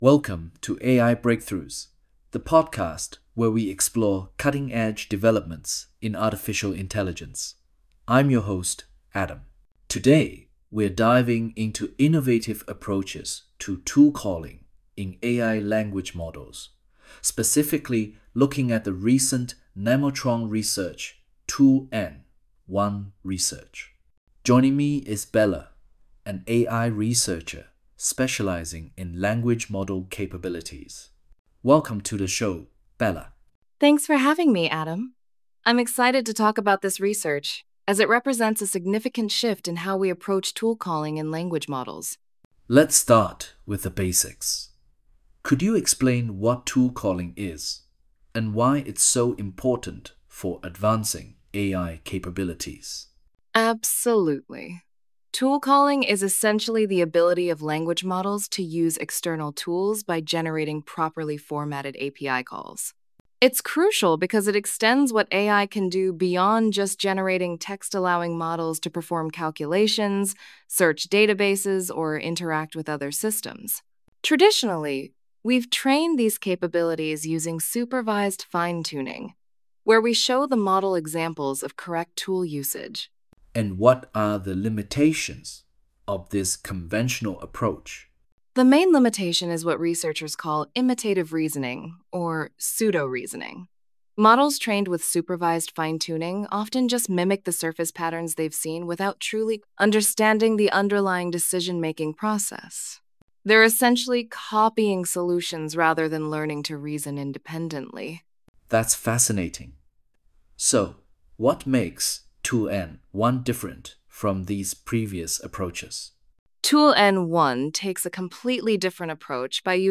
Converts PDF documents into customizable podcast-style audio content with configurable hosts, voices, and duration.
"monologue": false,
"host_voice": "Patient_Man",
"guest_voice": "Wise_Woman",